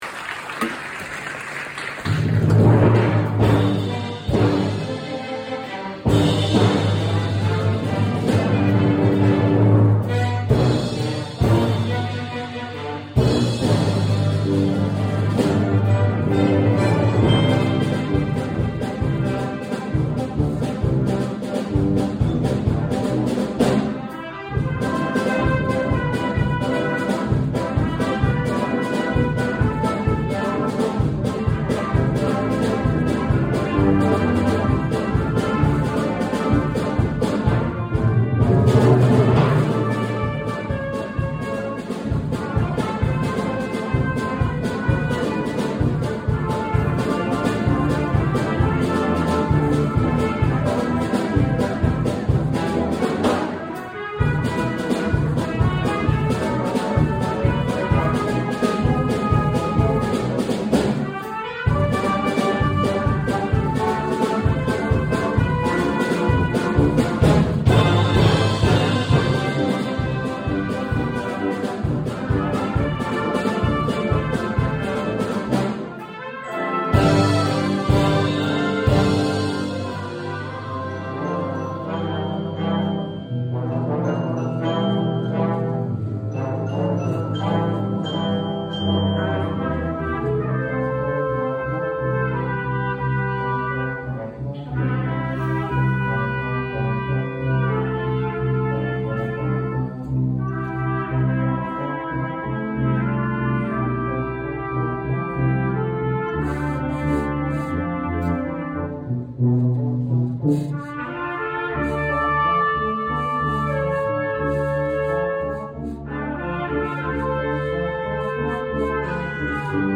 I Could Have Danced All Night | Brass Ensemble